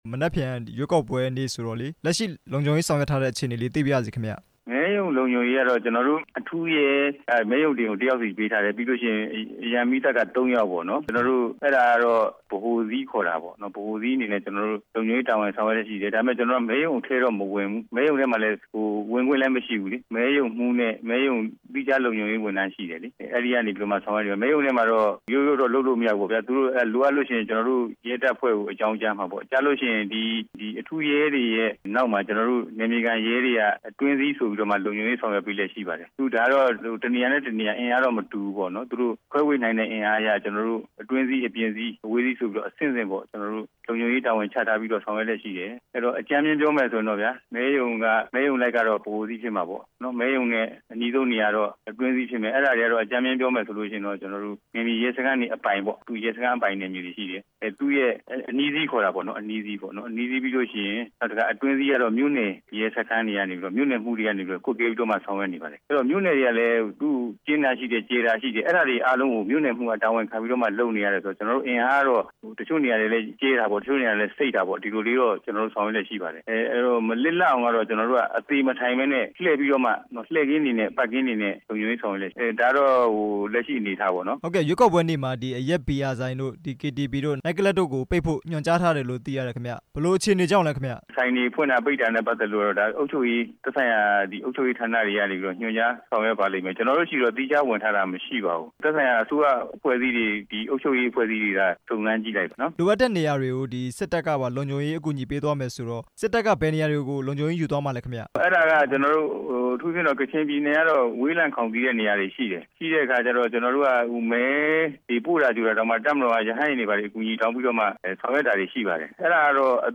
ရွေးကောက်ပွဲနေ့ လုံခြုံရေးဆောင်ရွက်မှု ဒုတိယရဲချုပ် သိန်းဦးနဲ့ မေးမြန်းချက်